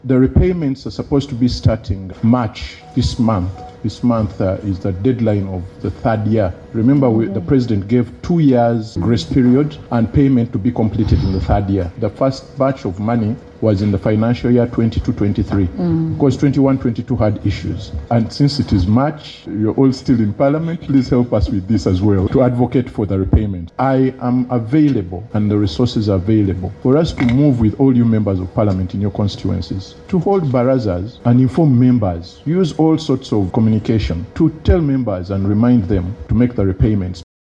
This revelation was made by the State Minister for Luwero Triangle and National Coordinator for the PDM, Hon. Dennis Galabuzi while appearing before the Public Accounts Committee (Central Government) on Tuesday, 03 March 2026.